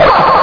bark1.ogg